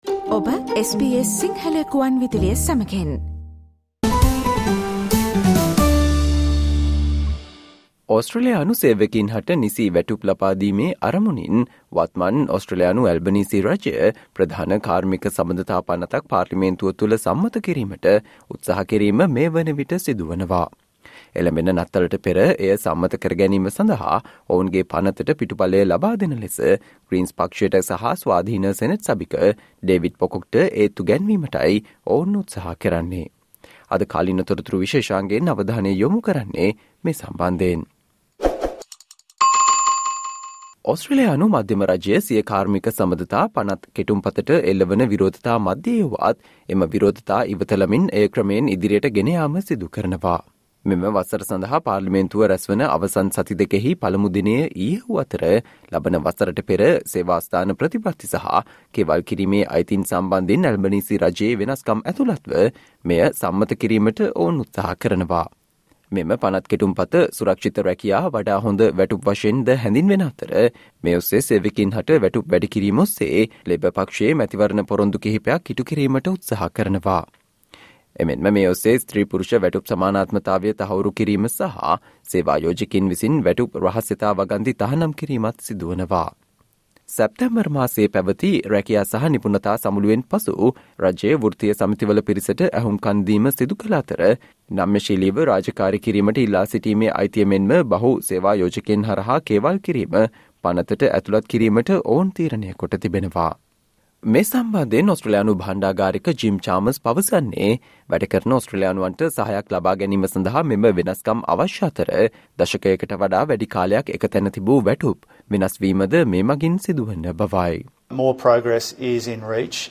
Today - 22 November, SBS Sinhala Radio current Affair Feature on Prime Minister Anthony Albanese pledge to take Australia's relationship with China forward